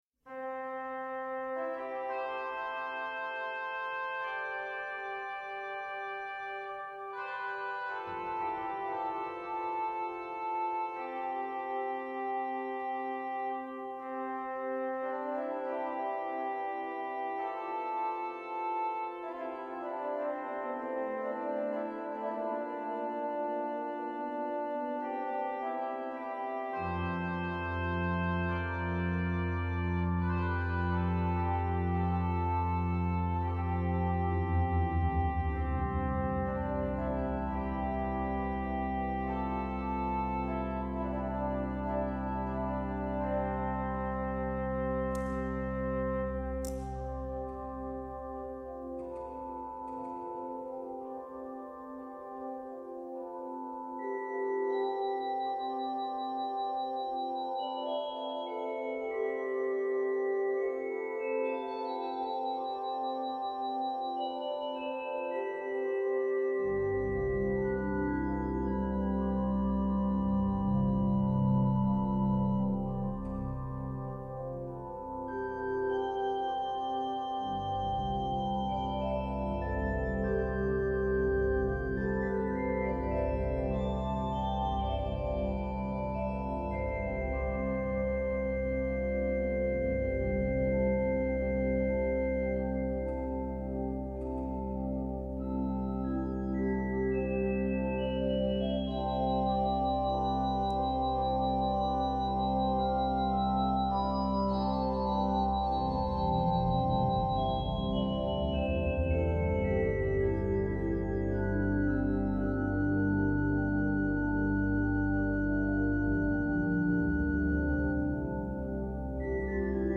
Concierto de Otoño a la Luz de las Velas
on the new Allen Quantum organ at the Cathedral of Valladolidin in Northern Spain.
all live recorded and published on this page.